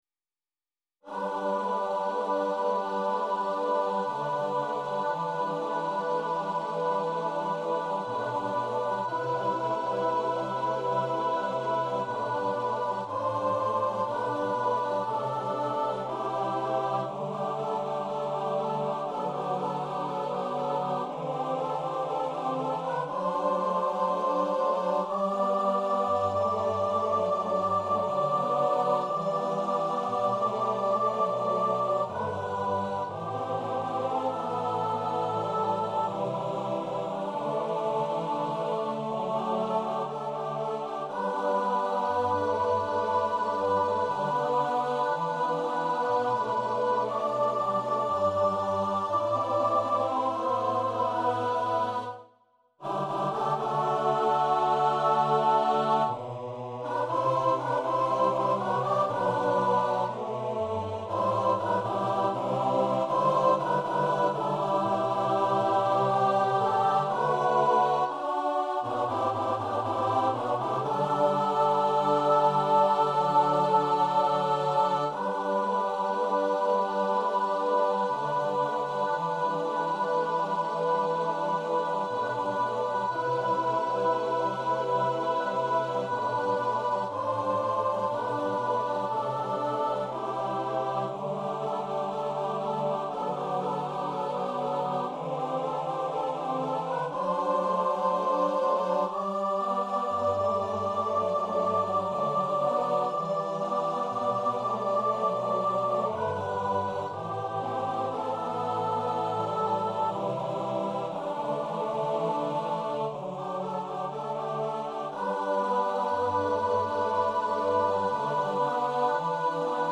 coro SATB